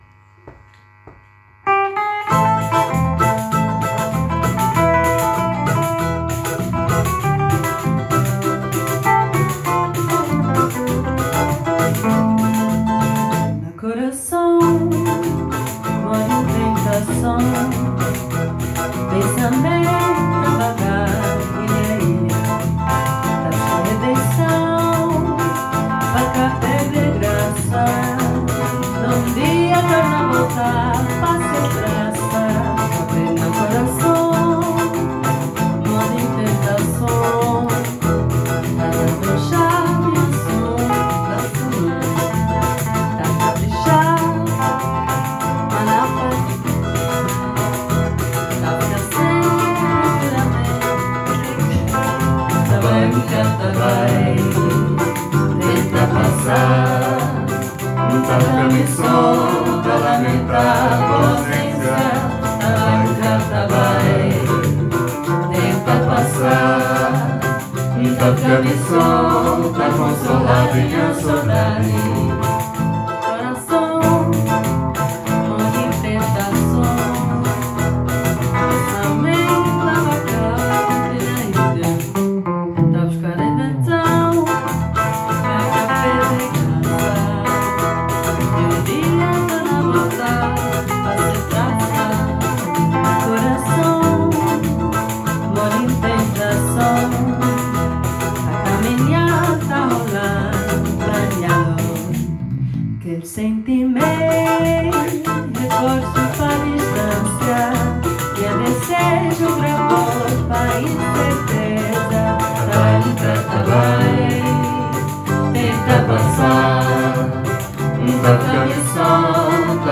Hier befindet sich eine Auswahl unseres Repertoires mit Sheets und einigen Audio-Aufnahmen der Stücke, die in den Proben gemacht wurden.